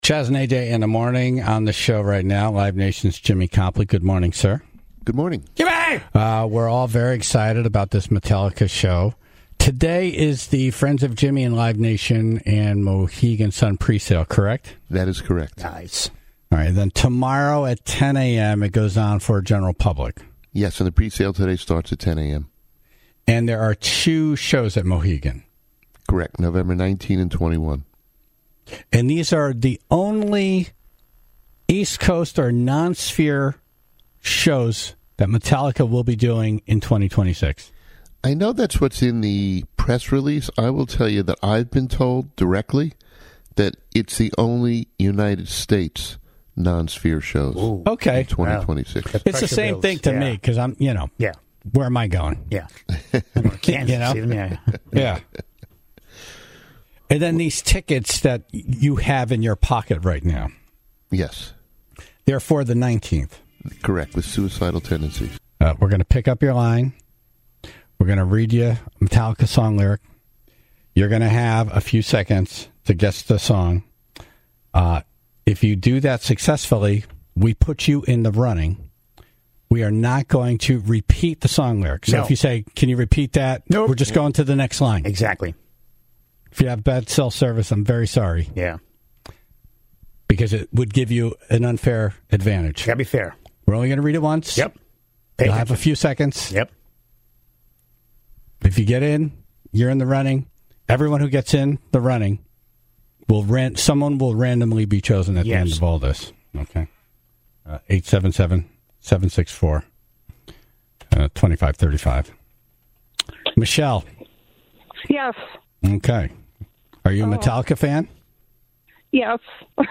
Identify a Metallica song from just the lyrics… no music, no growling, no help.